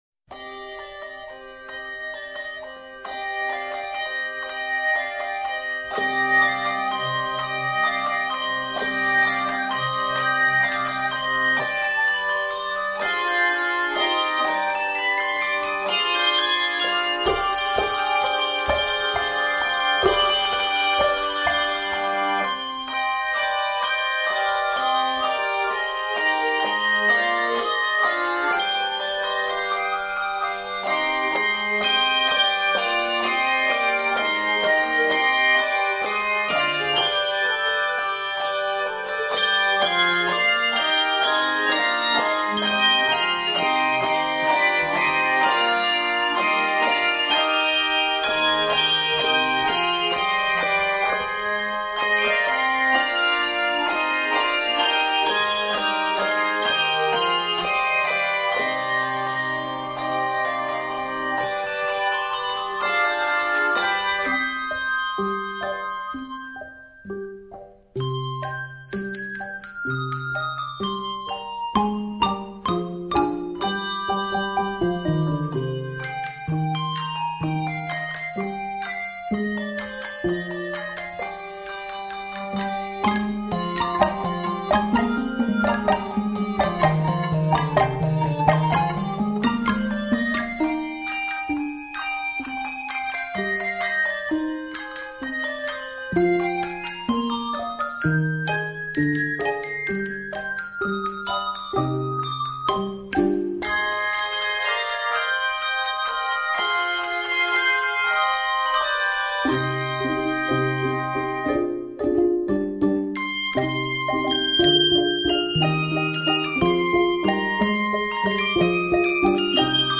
This fun and rhythmic setting of a beloved hymn
Set in c minor, this piece is 92 measures.